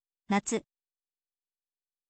natsu